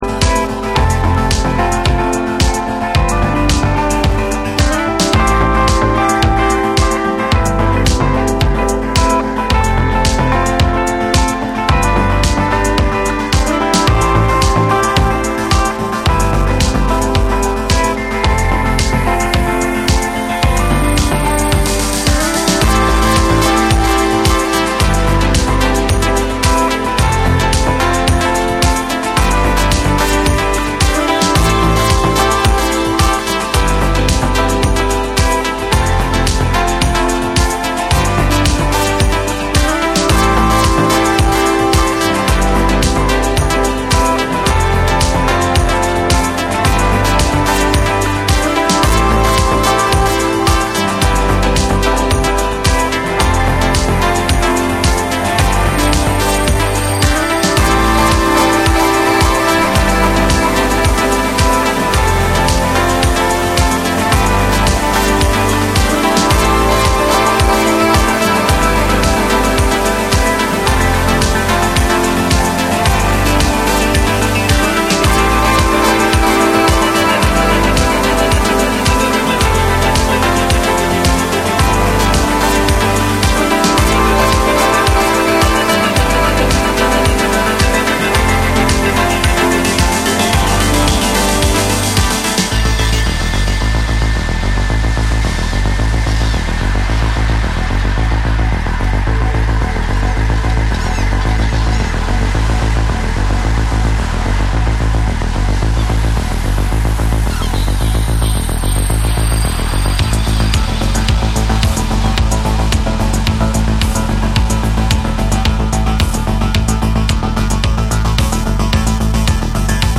多幸感溢れる煌めくシンセ、ギターが壮大に舞いながら展開するバレアリック・ナンバーを収録。
TECHNO & HOUSE